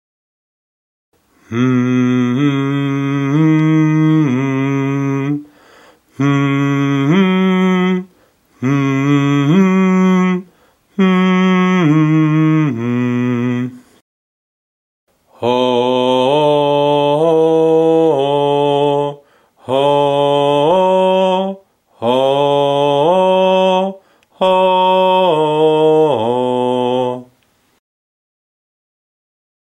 First I sing them humming, then with a “HOU” sound. These are real-life sounds with no autotuning or anything.